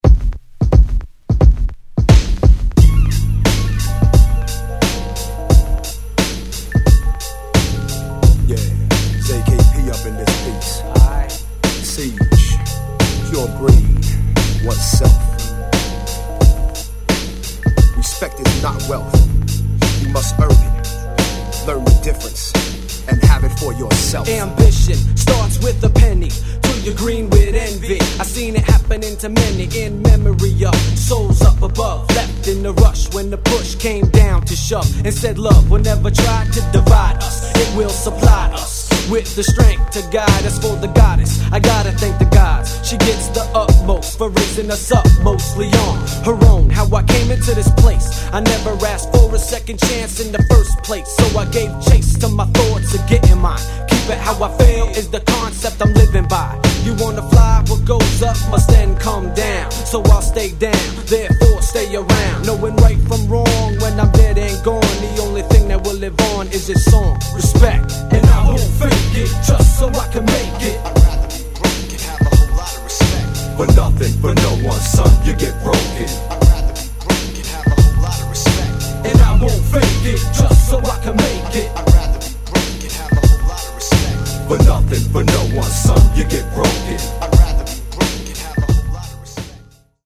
両面共に◎なNY Mid 90's Underground Hip Hop!!